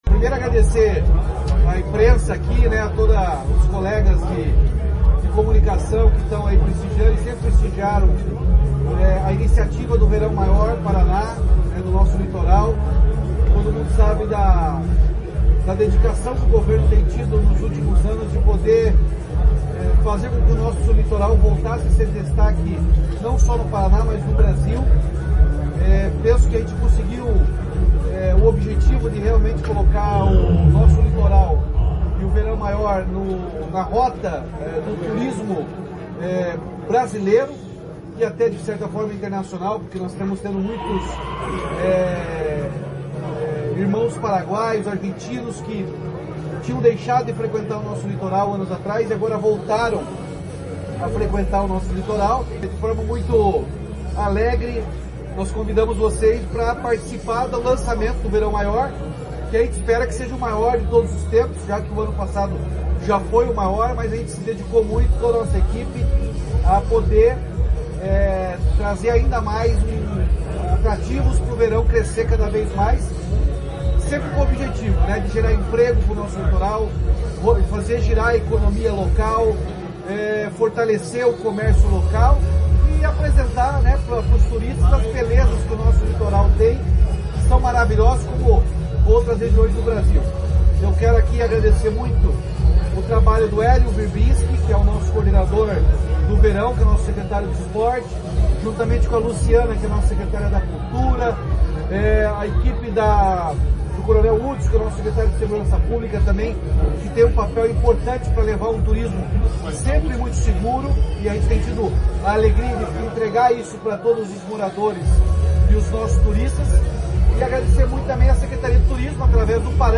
Sonora do governador Ratinho Junior sobre o Verão Maior Paraná 2025/2026